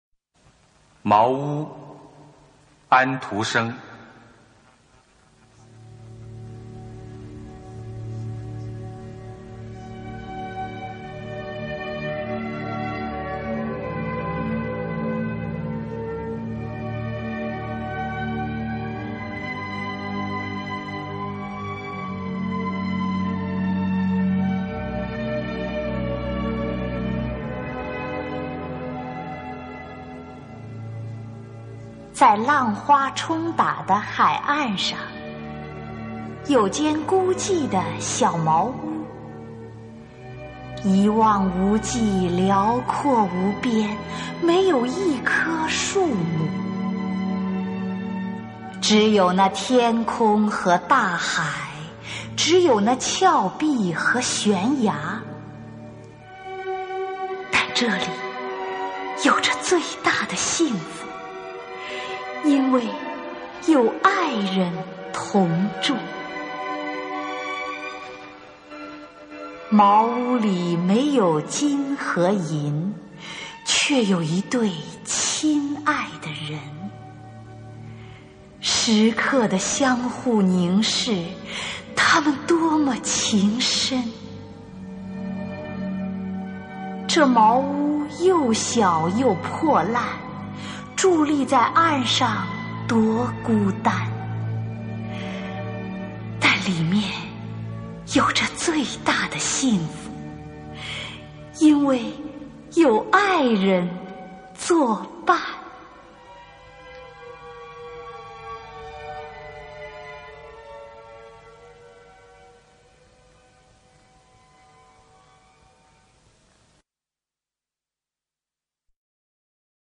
首页 视听 经典朗诵欣赏 丁建华、乔榛：外国爱情诗配乐朗诵